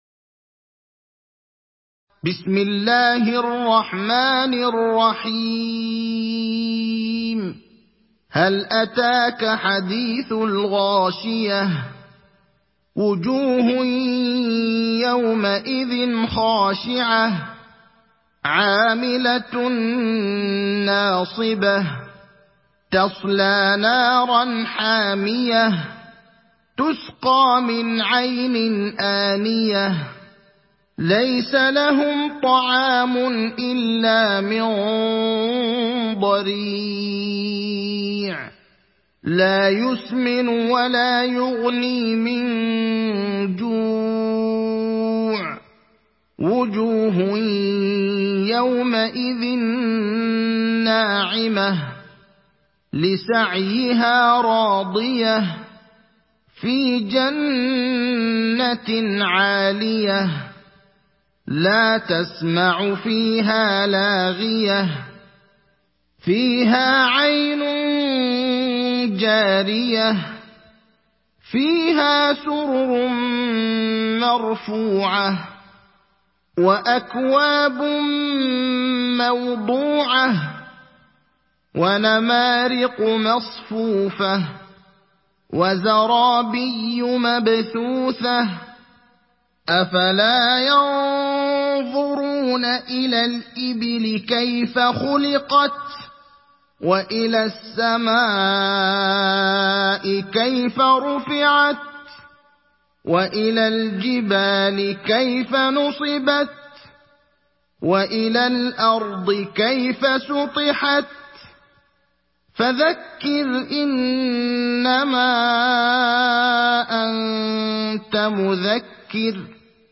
Surat Al Ghashiyah mp3 Download Ibrahim Al Akhdar (Riwayat Hafs)